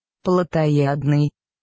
ПроизношениеПравить
Ru-плотоядный.ogg